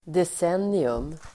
Ladda ner uttalet
Uttal: [des'en:ium]